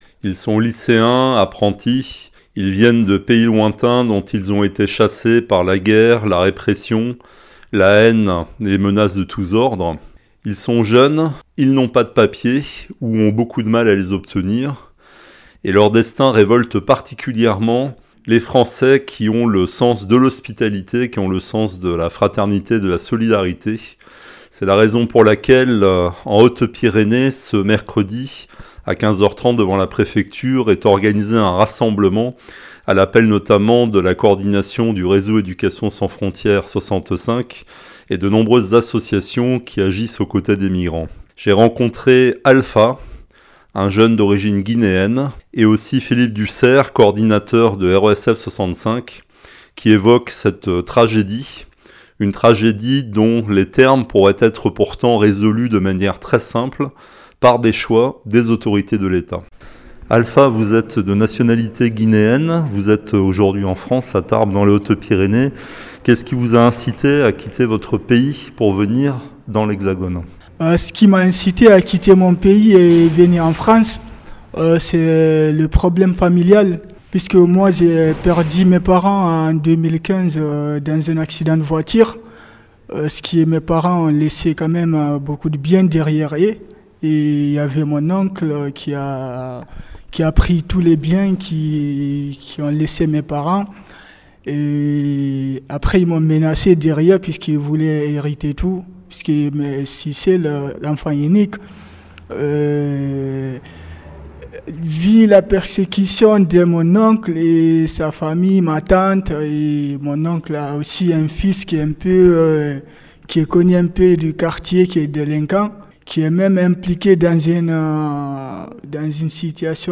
Interview Reportage